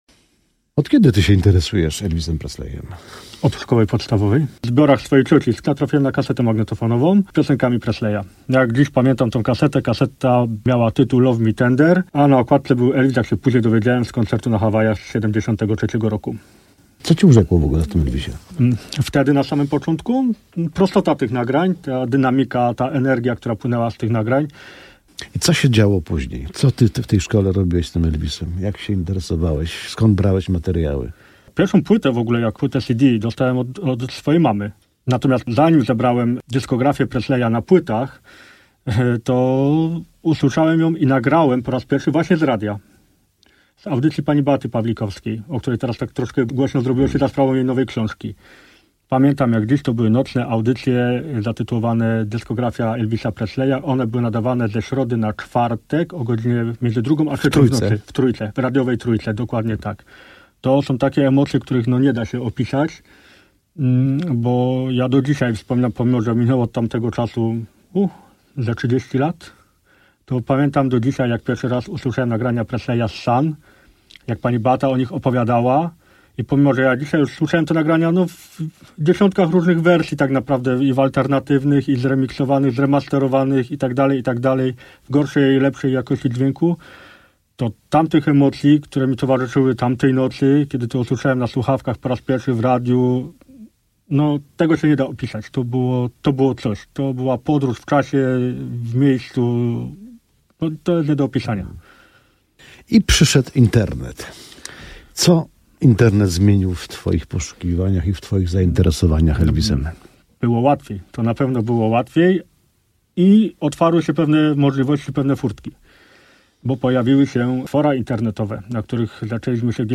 Posłuchajcie intrygujących wywiadów z ciekawymi ludźmi. Za mikrofonem dziennikarze RMF FM.